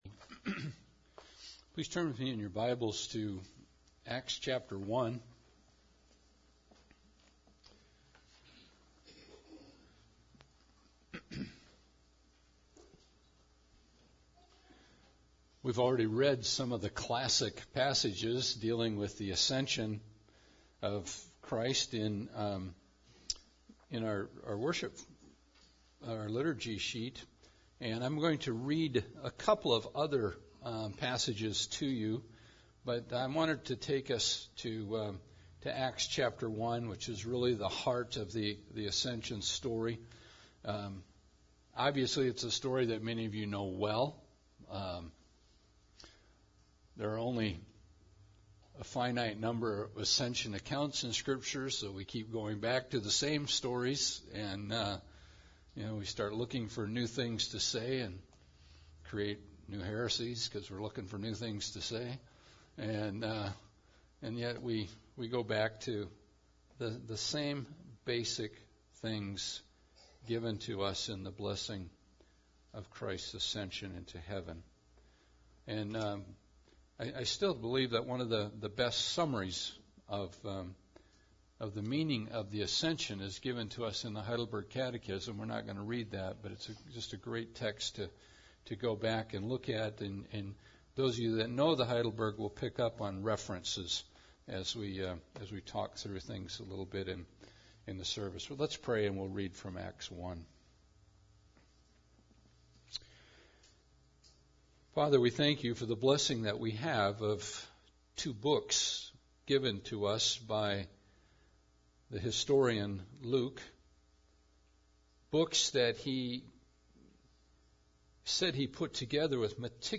Ascension Day Service